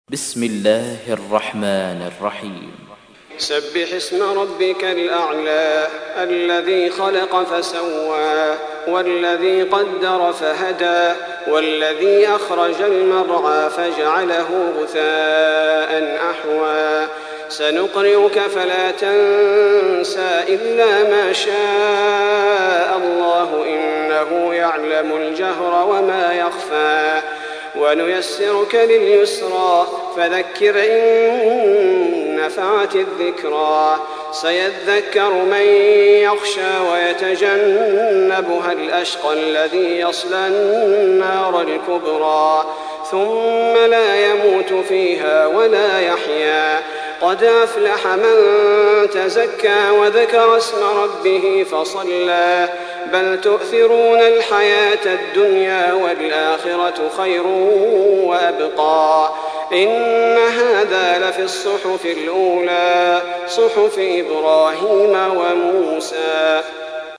تحميل : 87. سورة الأعلى / القارئ صلاح البدير / القرآن الكريم / موقع يا حسين